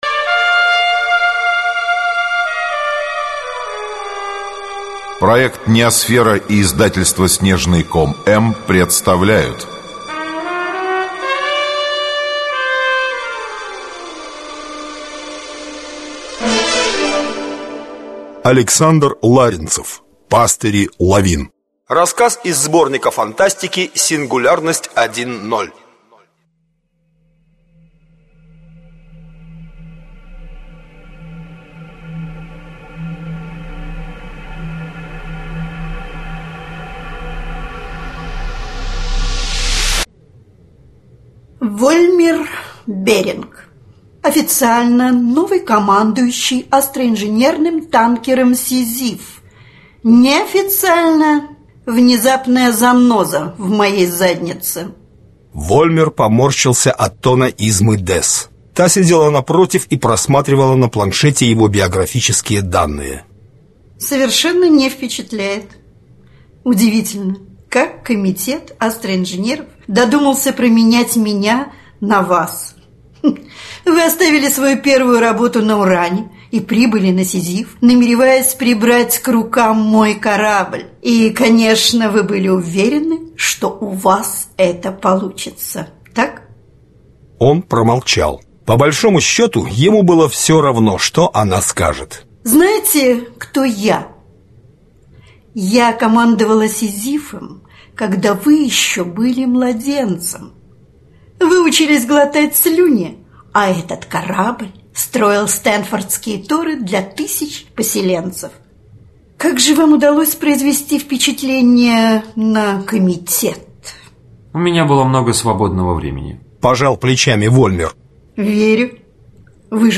Аудиокнига Пастыри лавин | Библиотека аудиокниг